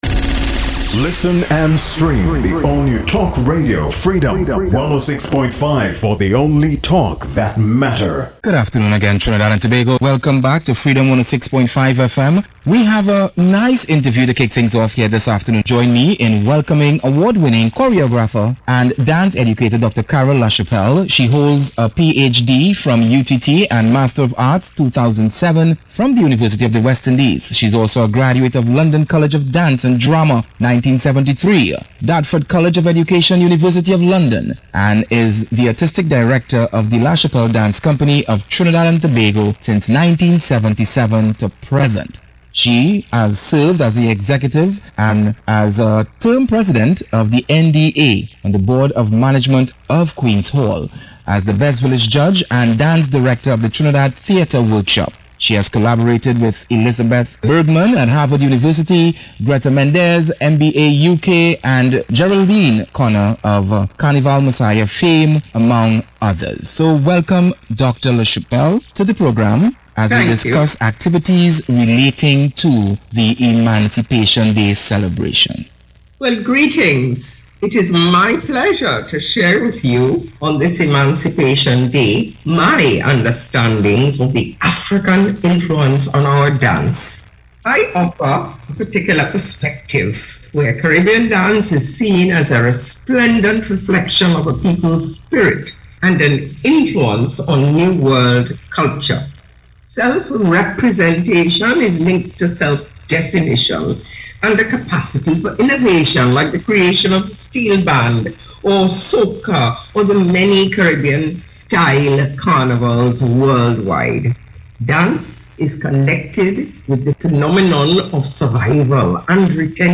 FEATURED GUEST